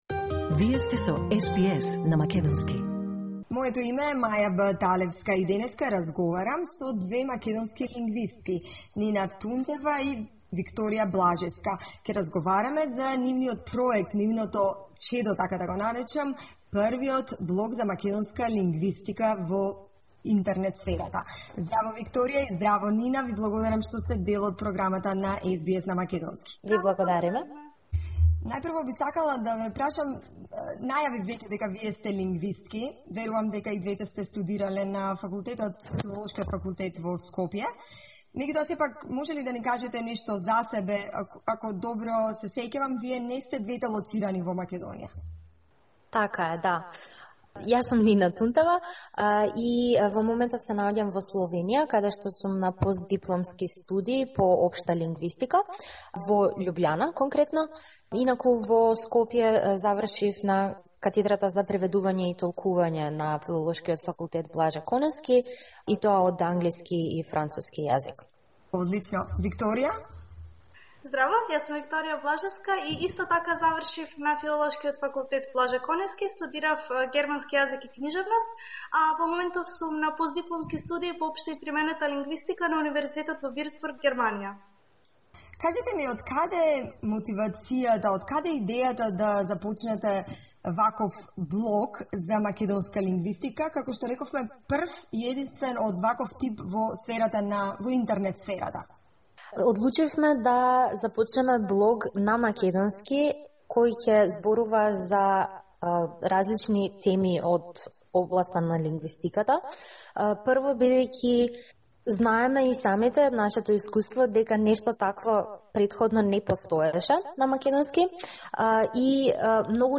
во разговор со СБС на македонски